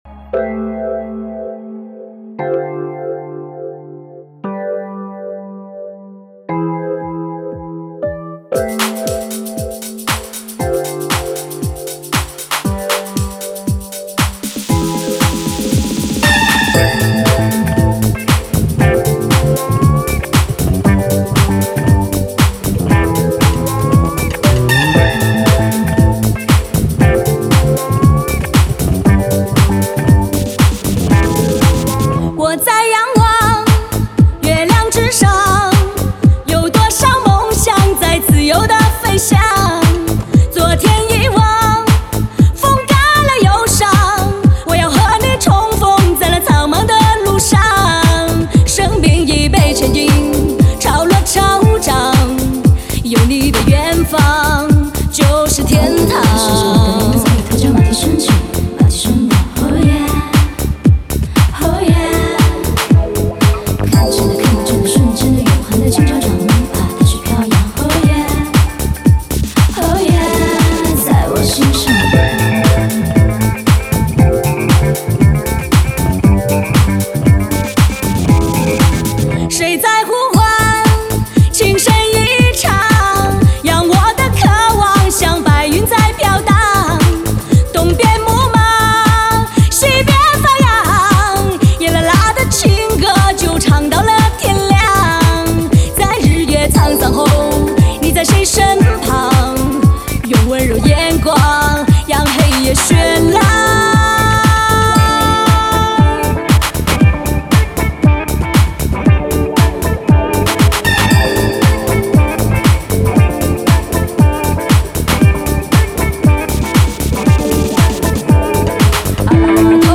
年度流行舞曲震撼出场，更新更狠更刺激，入侵重击你的酷舞帝国。